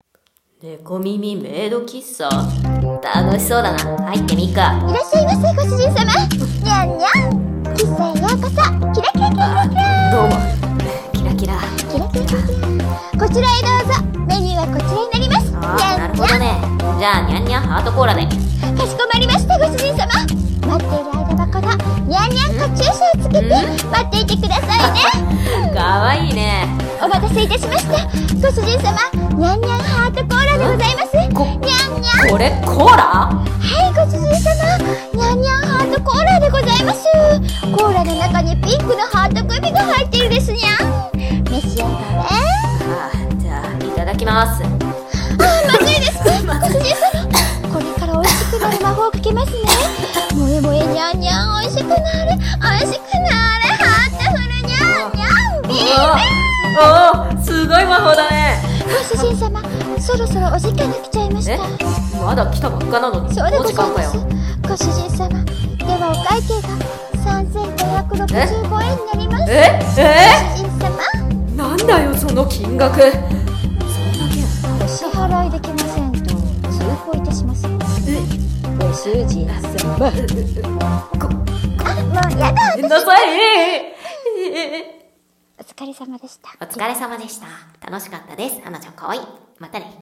【猫耳メイド喫茶へようこそ！ご主人様💖】【2人声劇】